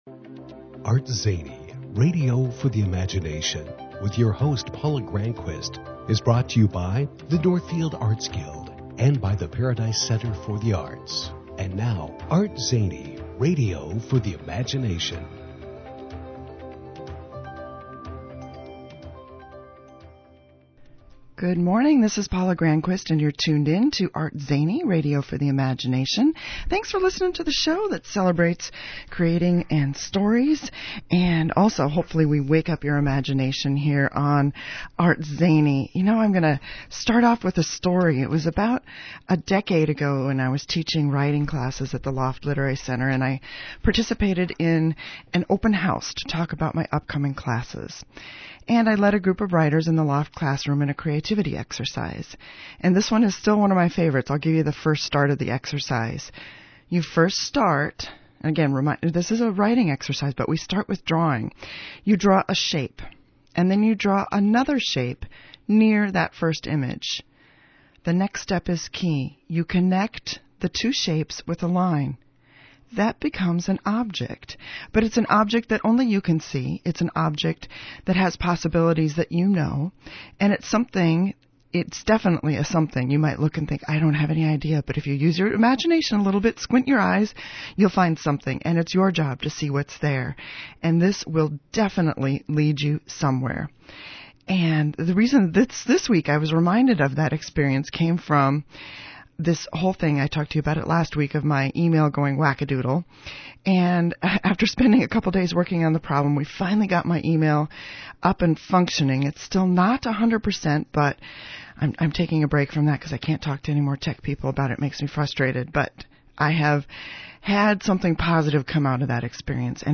Posts about Interviews
We discussed why people think they can’t draw, how to get started making art, my books, Sketchbook Skool and loads more. Here’s a recording of the show